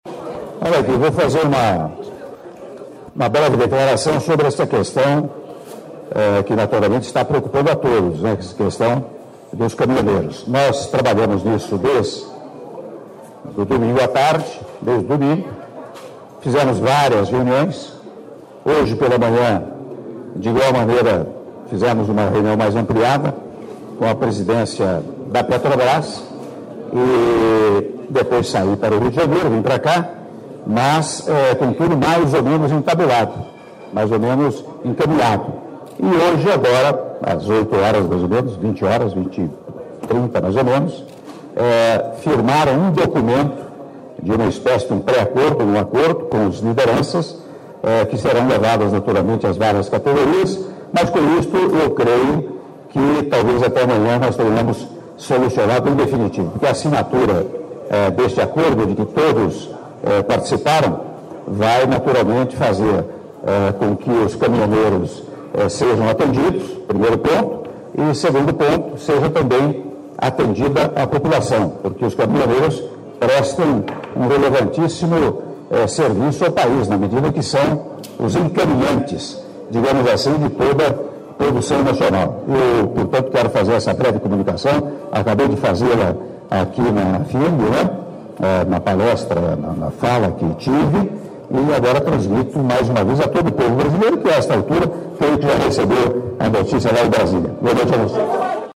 Áudio da entrevista coletiva concedida pelo Presidente da República, Michel Temer, após a Solenidade em Comemoração ao Dia da Indústria 2018 e Cerimônia de Posse do Presidente e da Nova Diretoria da FIEMG -Belo Horizonte/MG- (01min38s) — Biblioteca